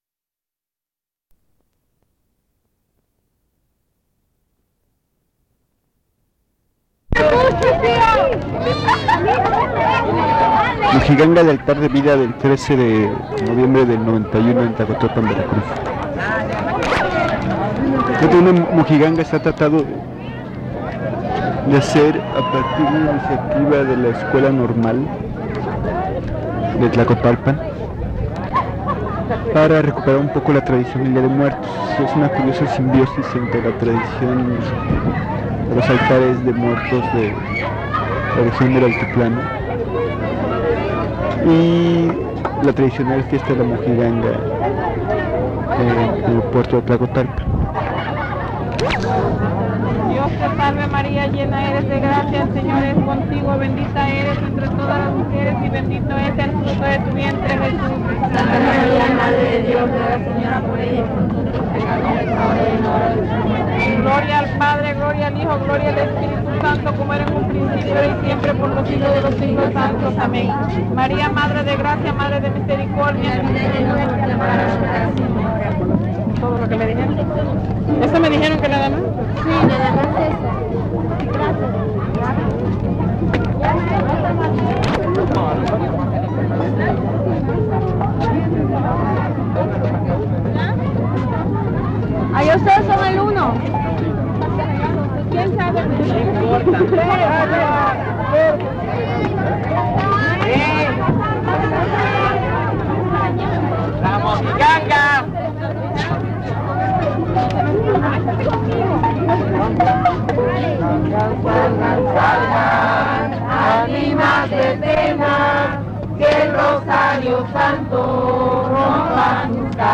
Genero o forma Entrevista
Ubicación Tlacotalpan, Veracruz, Mexico